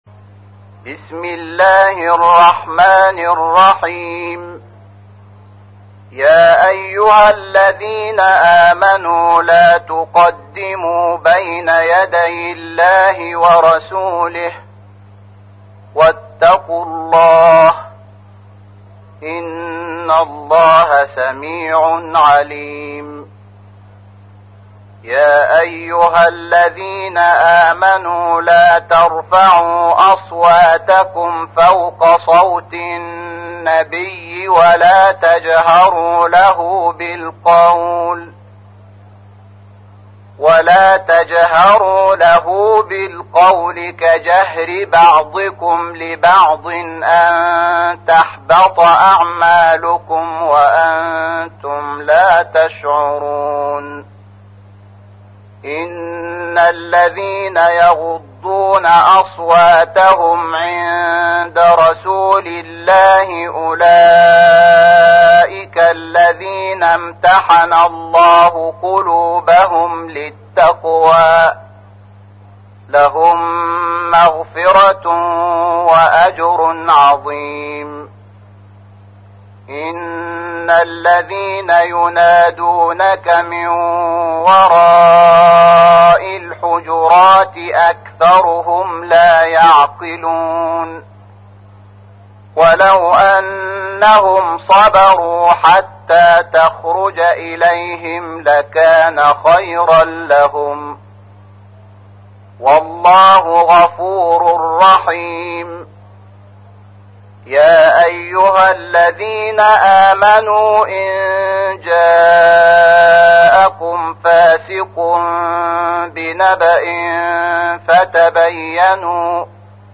تحميل : 49. سورة الحجرات / القارئ شحات محمد انور / القرآن الكريم / موقع يا حسين